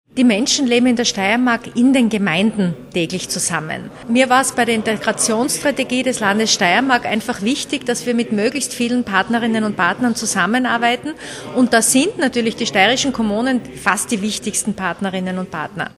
O-Ton: Partnerschaften mit dem Integrationsressort
Integrationslandesrätin Bettina Vollath: